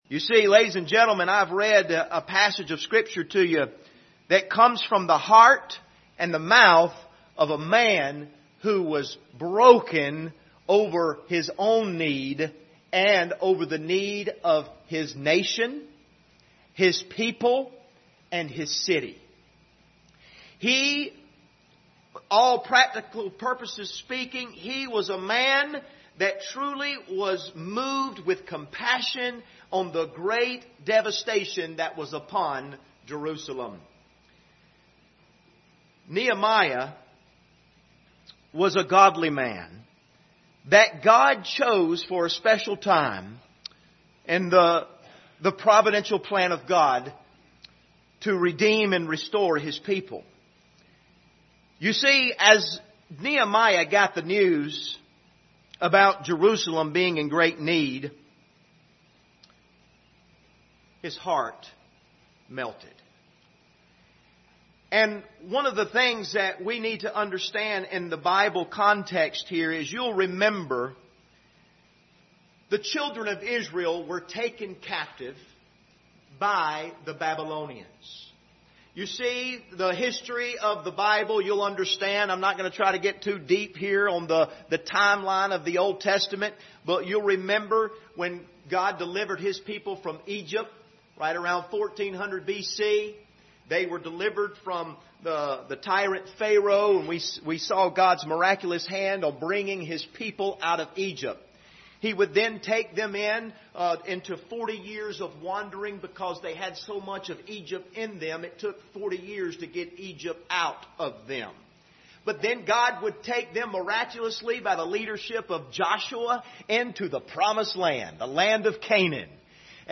Passage: Nehemiah 1 Service Type: Sunday Morning